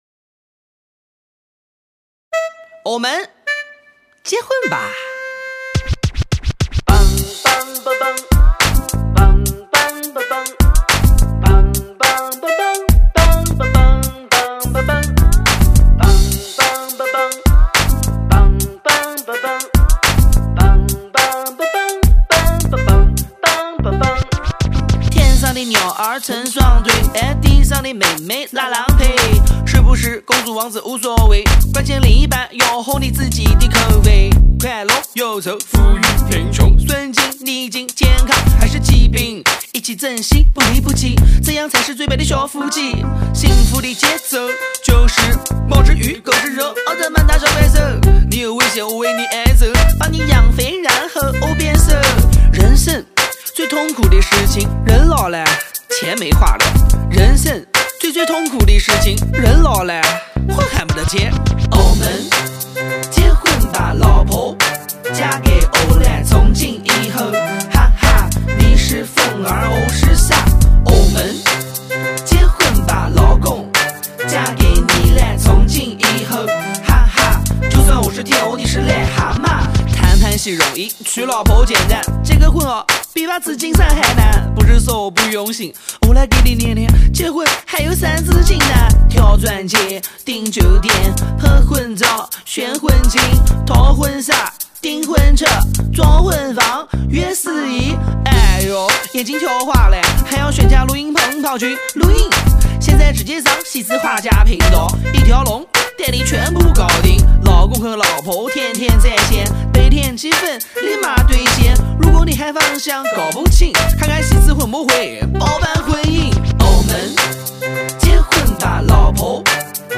南京录音棚